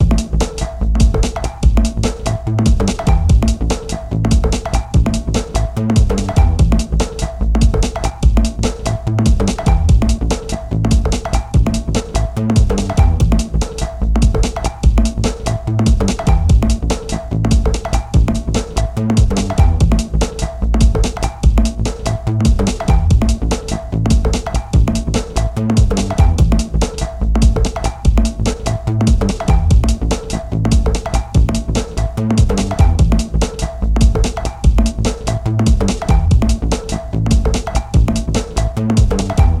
ループするBGM。
アップテンポな曲です。